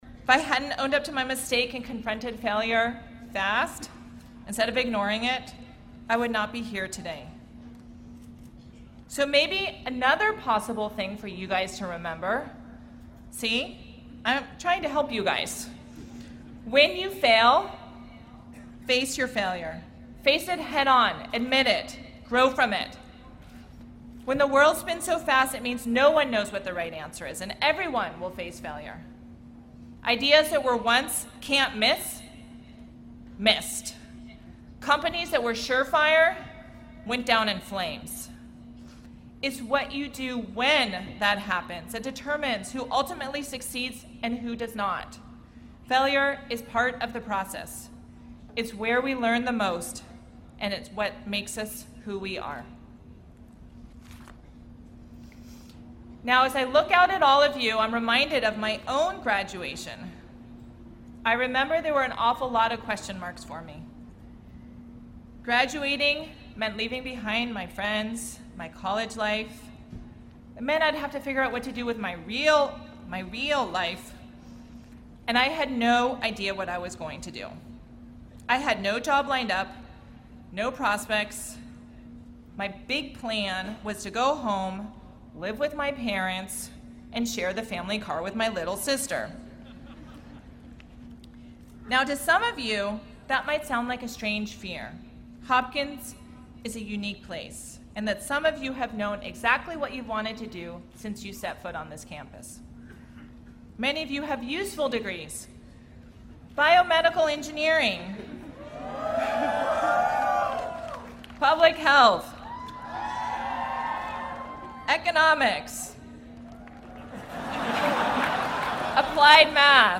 公众人物毕业演讲 第198期:苏珊沃西基2014在约翰霍普金斯大学(11) 听力文件下载—在线英语听力室